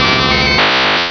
-Replaced the Gen. 1 to 3 cries with BW2 rips.
raichu.aif